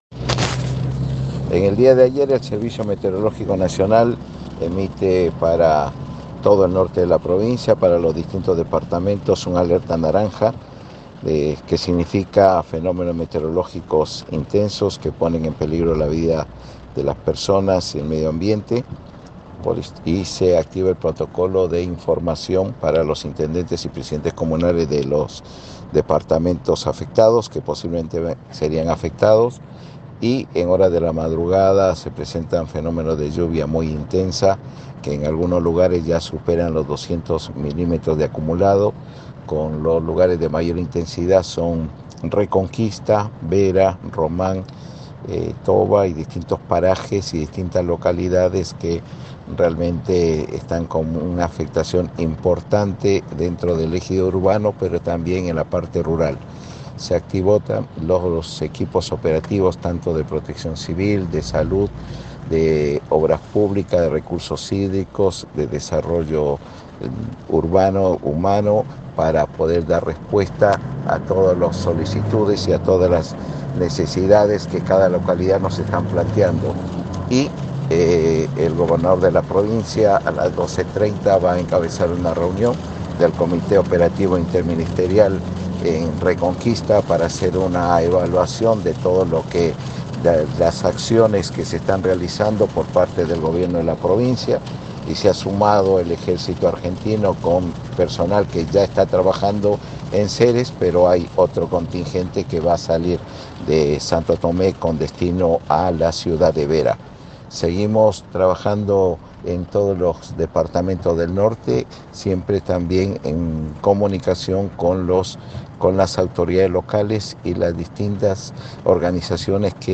AUDIO: Marcos Escajadillo, secretario de Protección Civil